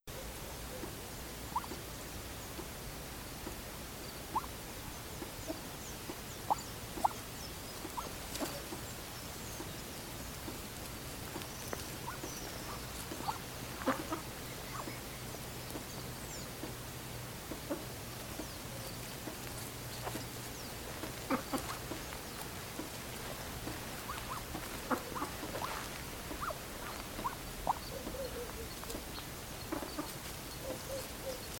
Su canto es un bufido sordo y pesado.
Cairina moschata - Pato criollo.wav